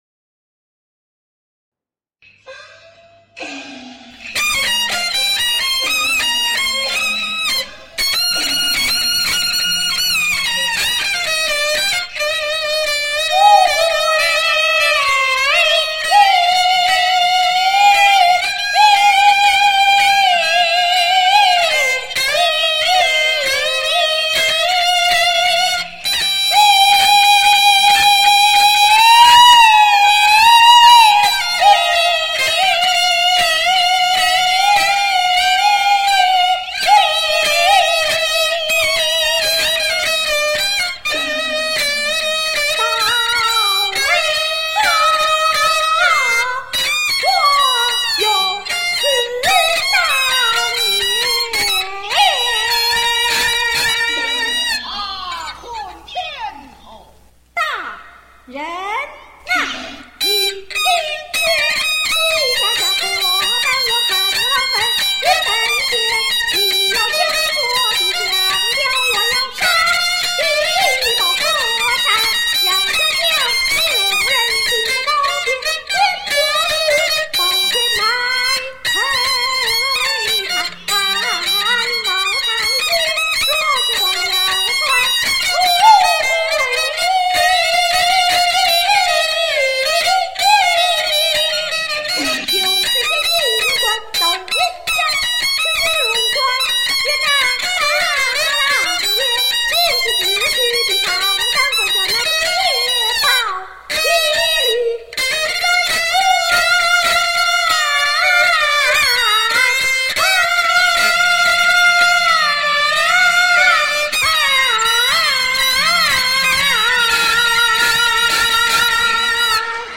京剧《穆桂英抖威风勇似当年》